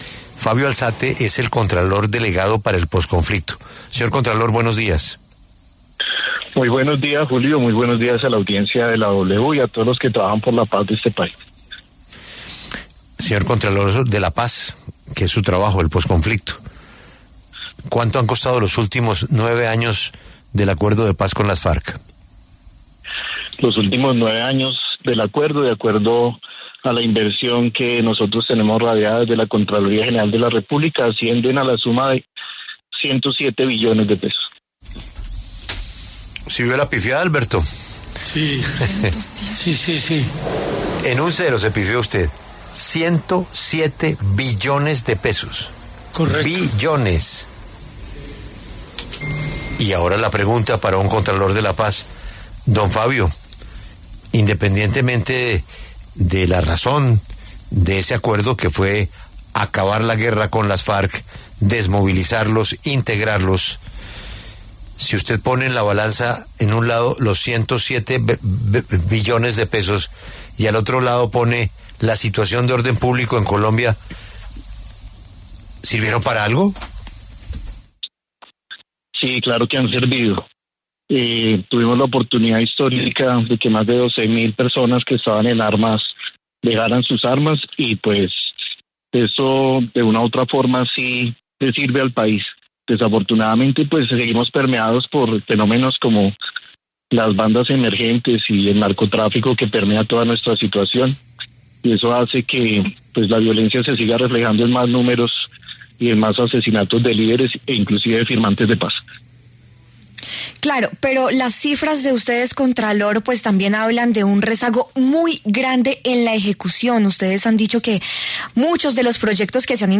Fabio Alzate, contralor delegado para el posconflicto, alertó en La W que la falta de articulación entre instituciones ha sido una de las causas de la falta de ejecución.